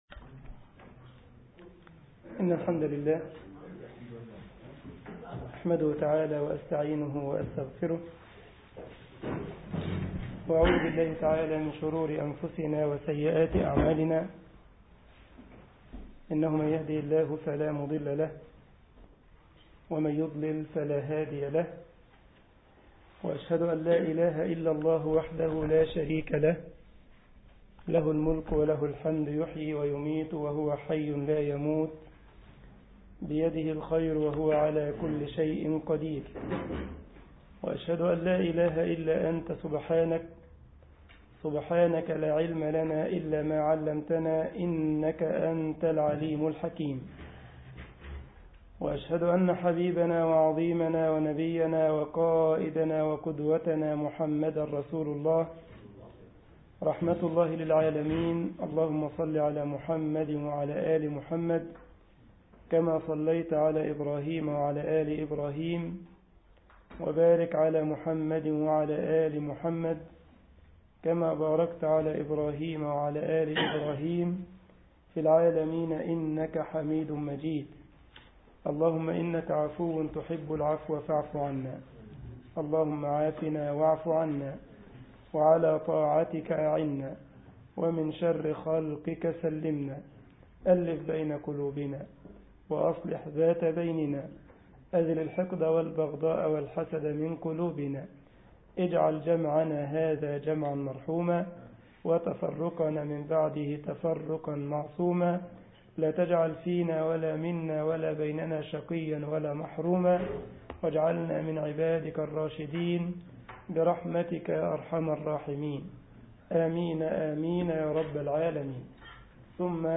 مسجد الجمعية الإسلامية بالسالند ـ ألمانيا درس